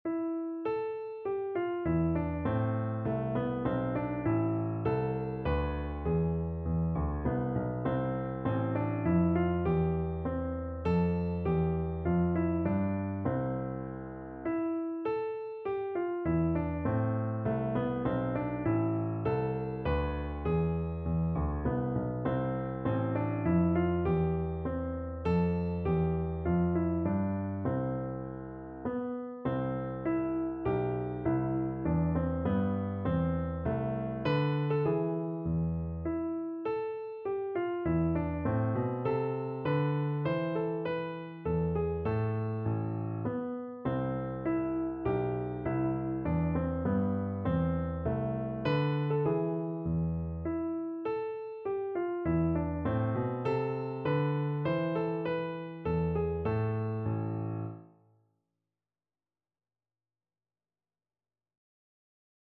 Classical Purcell, Henry Minuet in E Minor Piano version
No parts available for this pieces as it is for solo piano.
3/4 (View more 3/4 Music)
Moderato
Piano  (View more Intermediate Piano Music)
Classical (View more Classical Piano Music)